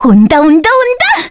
Rephy-Vox_Halloween_Skill3_kr.wav